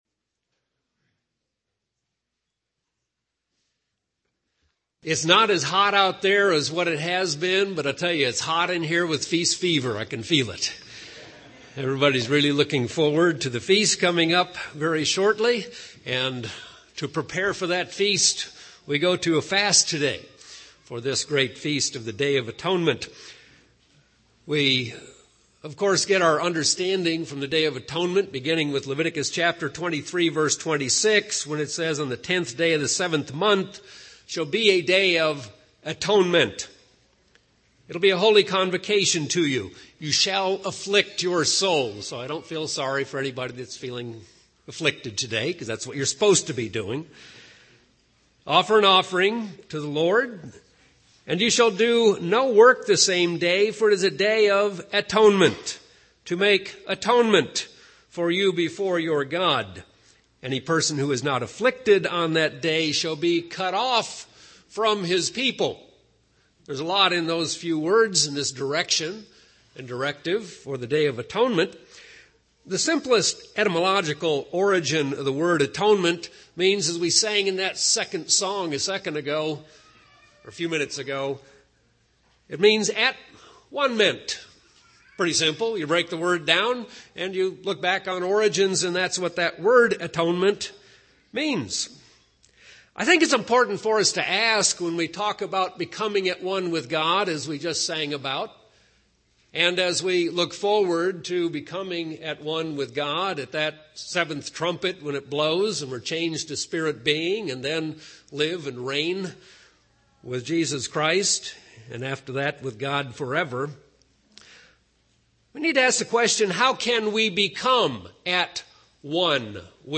(Atonement) UCG Sermon Studying the bible?